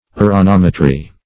Meaning of uranometry. uranometry synonyms, pronunciation, spelling and more from Free Dictionary.
Search Result for " uranometry" : The Collaborative International Dictionary of English v.0.48: Uranometry \U`ra*nom"e*try\, n. [Gr.